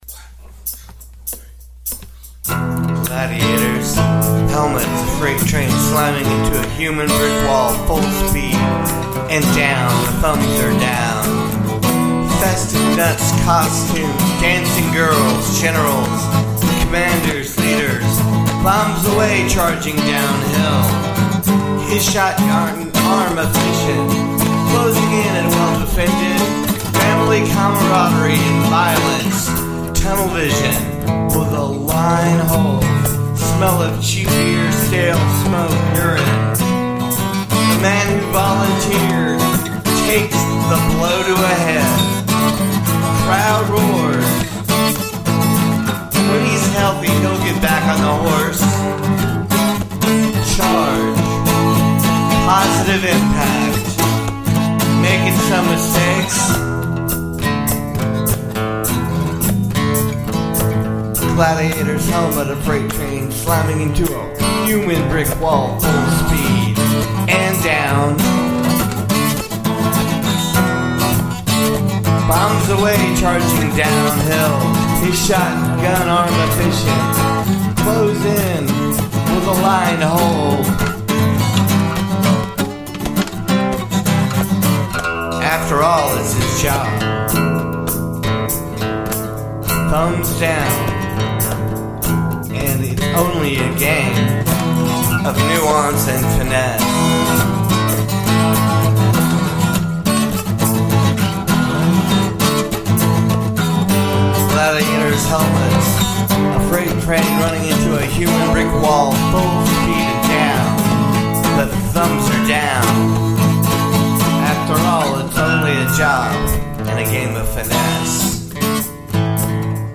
the Gladiator, (prose set to music)
Manic Beatnik Riffing